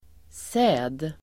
Uttal: [sä:d]